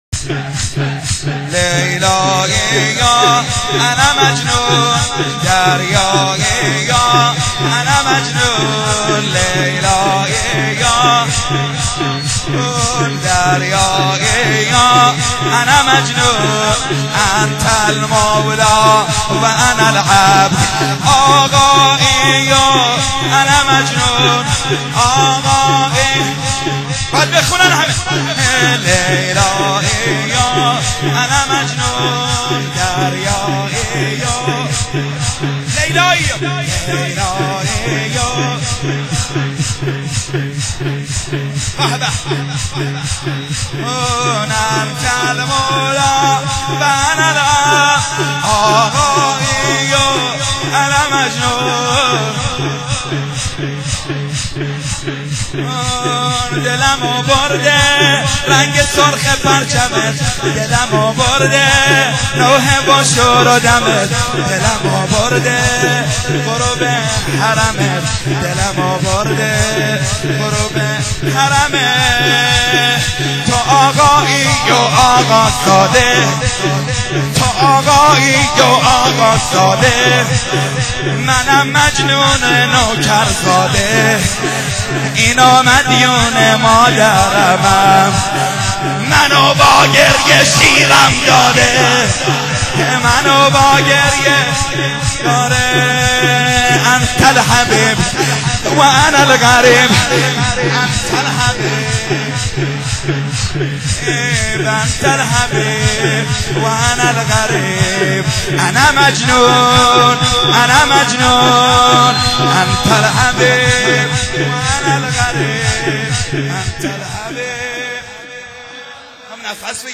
شور.wma